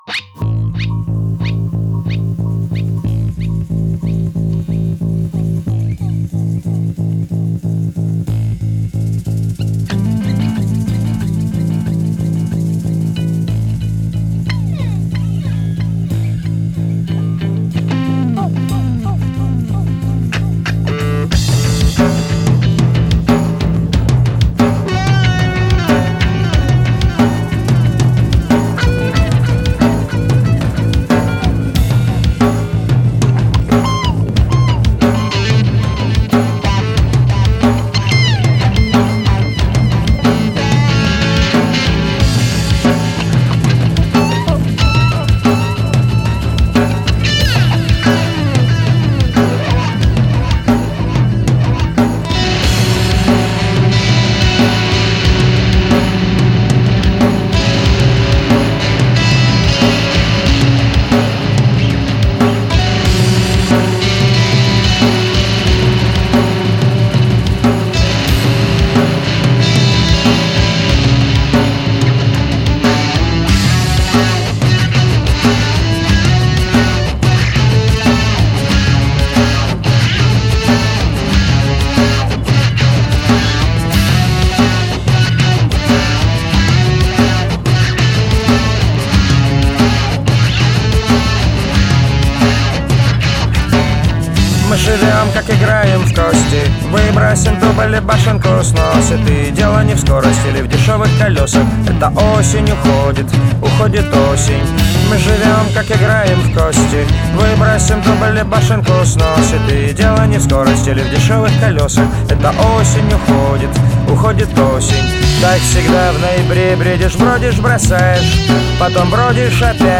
урбанизированных поп-песнях